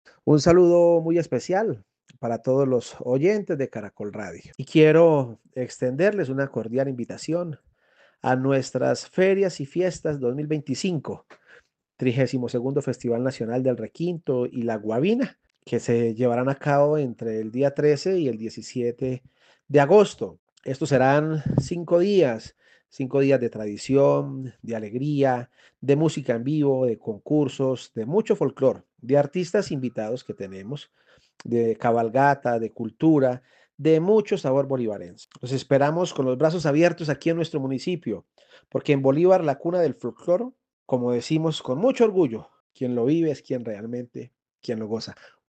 Róniver Raúl Pérez, alcalde de Bolívar, Santander